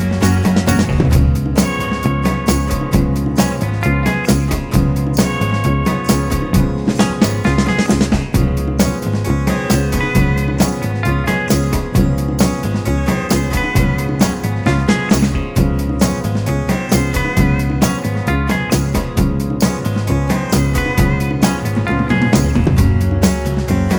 Rhythm Guitar Only Mix Pop (1960s) 3:35 Buy £1.50